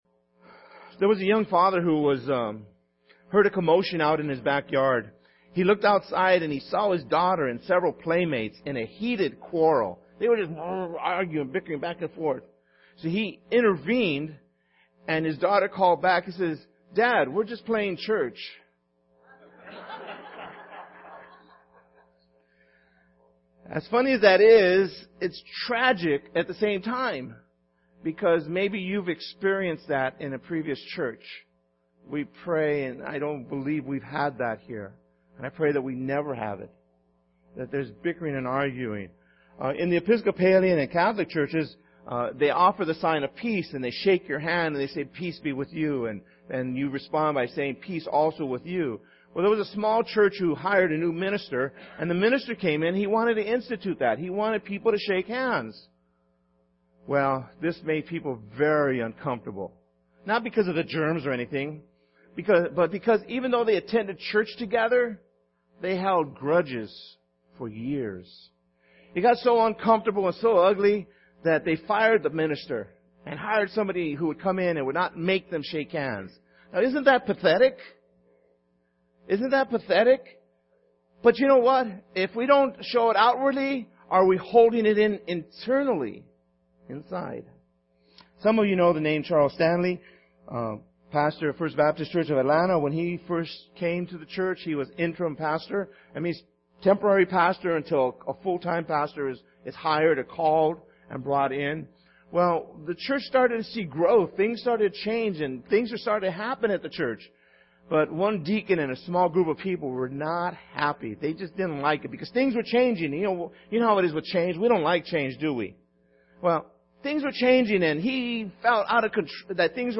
January 18th 2015 Sermon